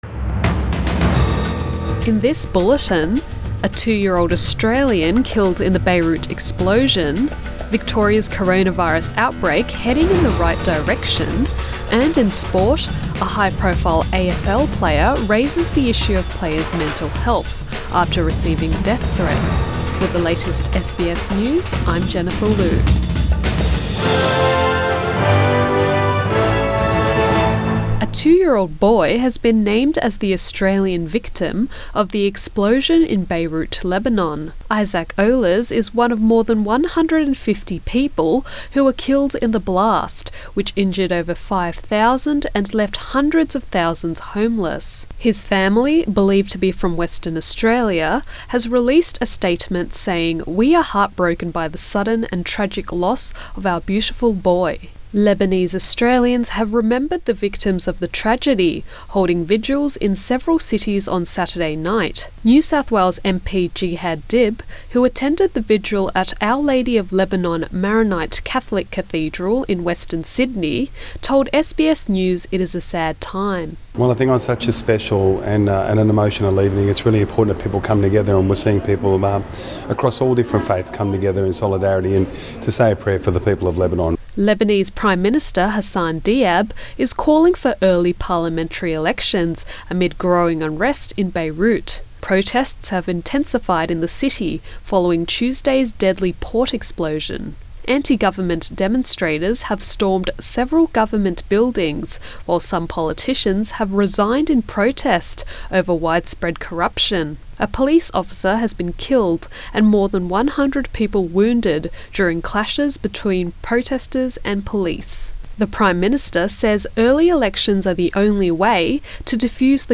AM bulletin 9 August 2020